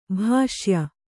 ♪ bhāṣya